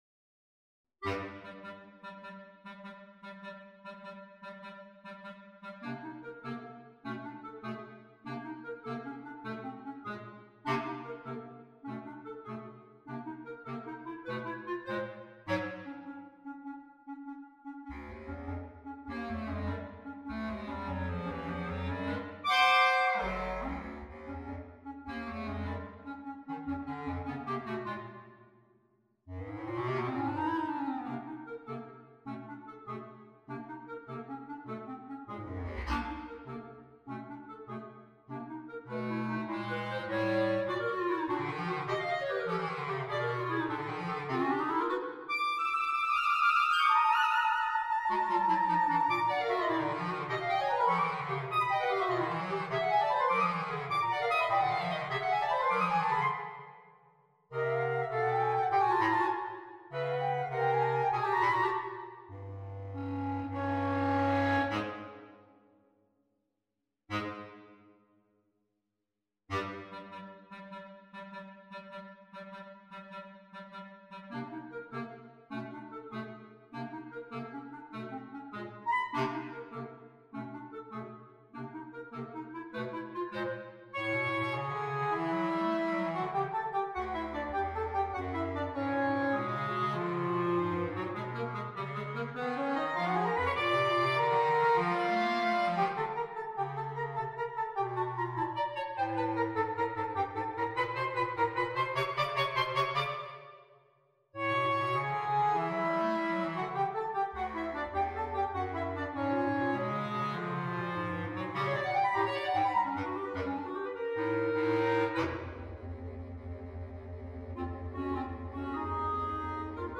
per 2 clarinetti e clarinetto basso